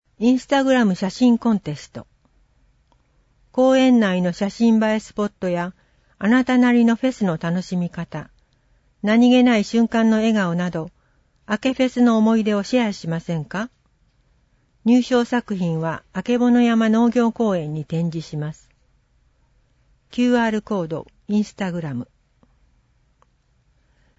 令和6年(2024年)10月号音訳版
• 発行は、柏市朗読奉仕サークルにご協力いただき、毎号行っています。